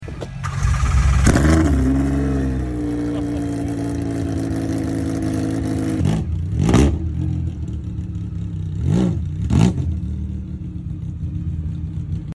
Cold start of the 700hp sound effects free download